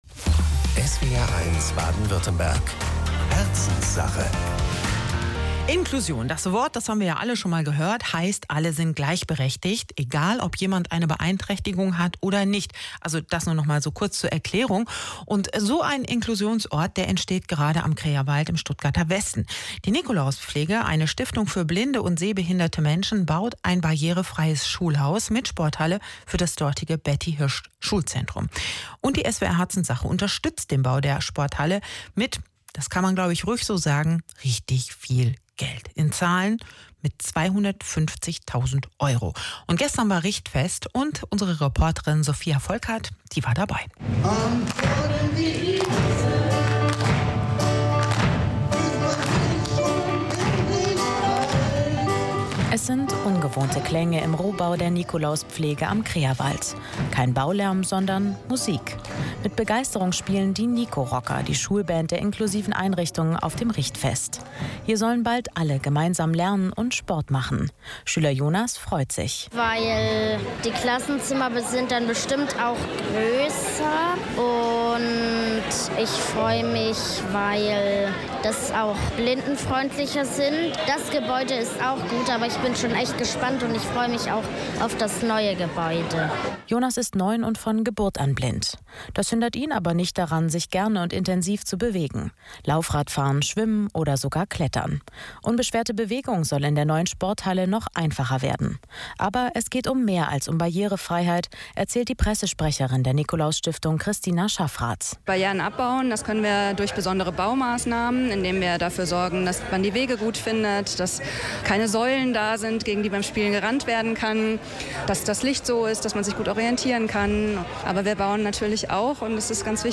In Stuttgart entsteht aktuell eine neue Sporthalle, die u.a. von blinden und sehbehinderten SchülerInnen genutzt werden soll. SWR1 war beim Richtfest Letzte Woche mit dabei